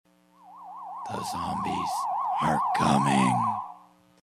The Zombies Are coming Sound Effect.mp3 📥 (265.1 KB)